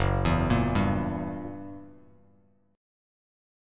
F#7b9 chord